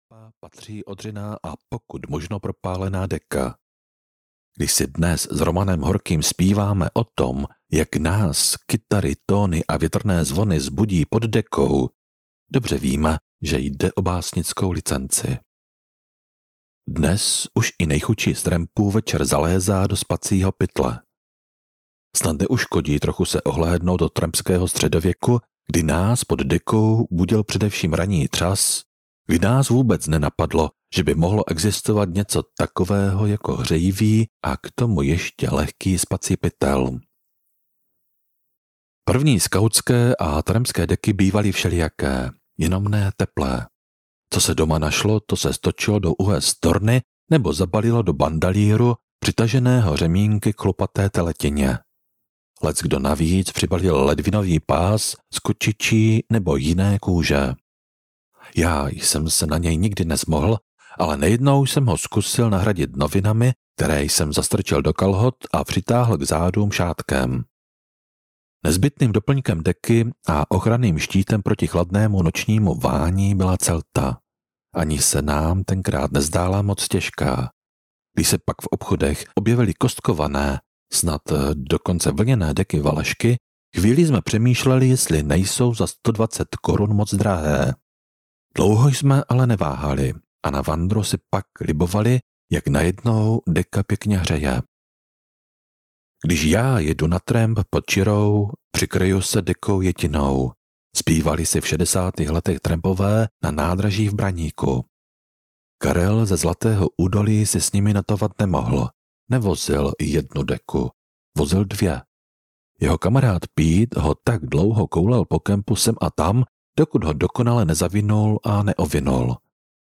Stopou toulavou audiokniha
Ukázka z knihy